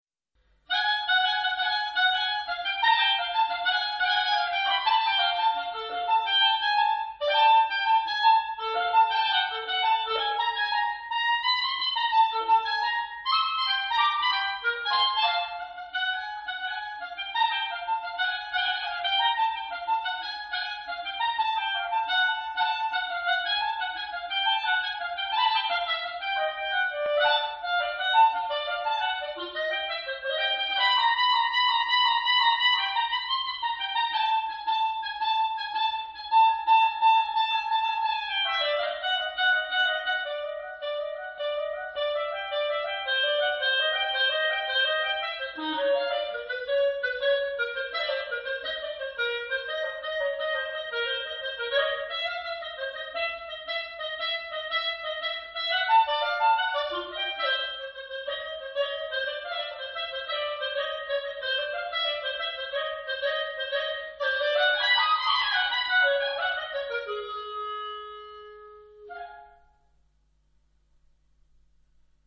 Pièce pour clarinette seule